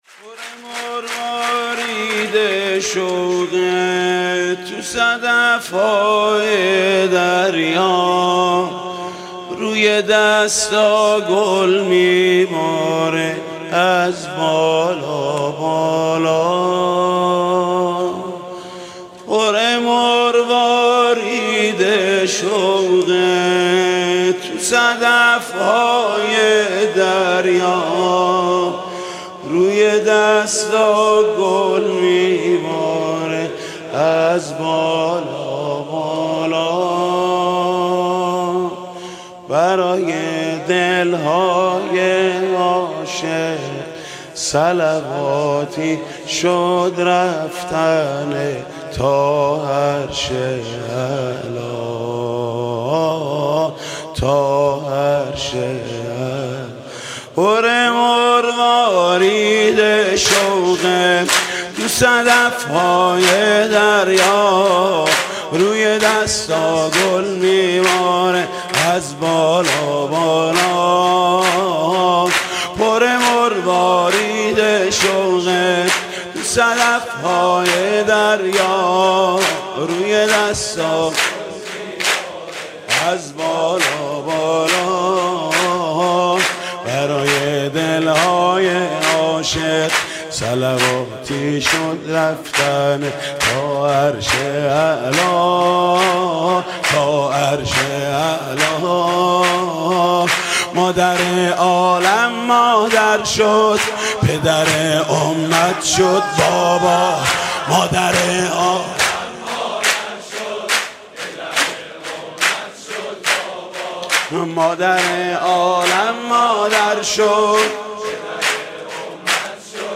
سرود: پر مروارید شوق تو صدف های دریا